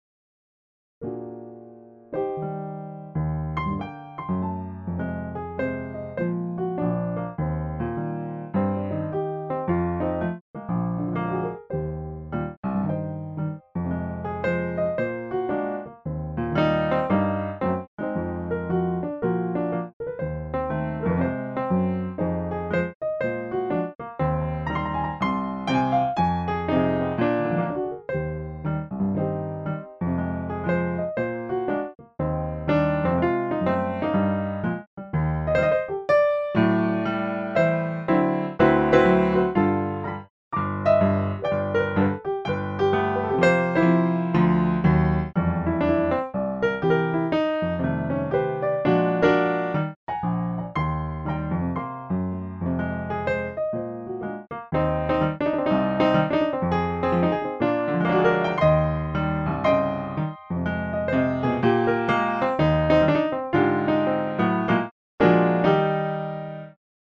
au piano